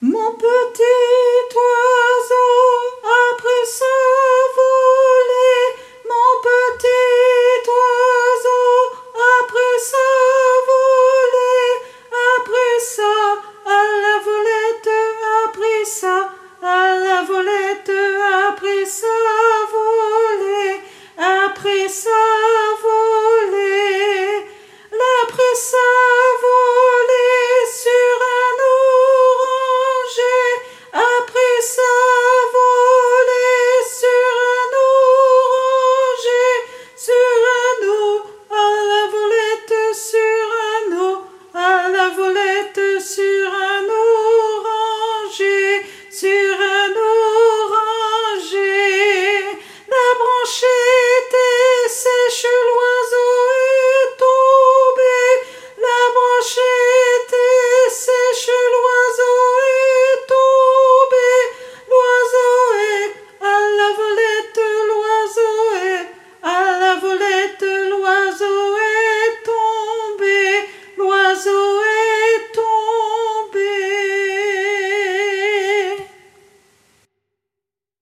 Tenor